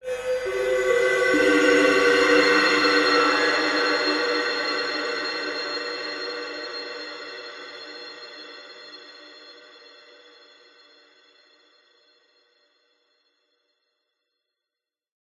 Звуки фэнтези
Фантастическое вздутие, таинственная музыка, металлический отзвук